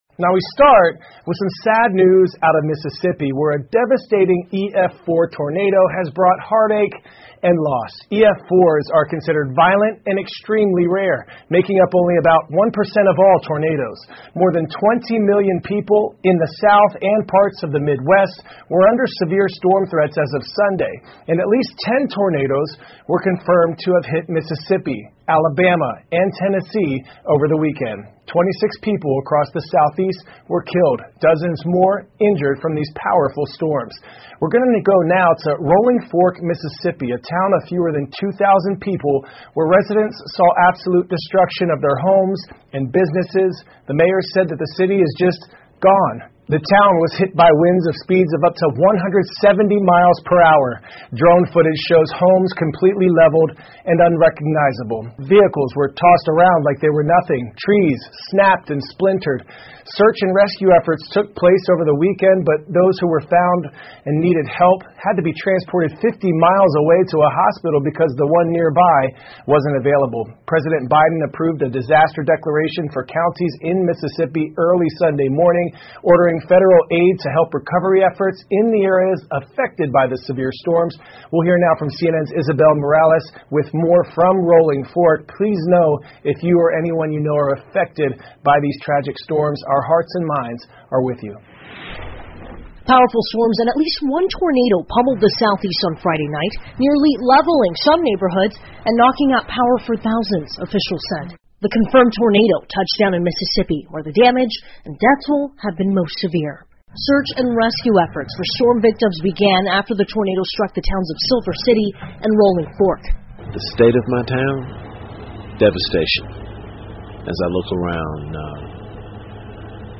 CNN美国有线新闻 强风暴袭击美国已致26人遇难 听力文件下载—在线英语听力室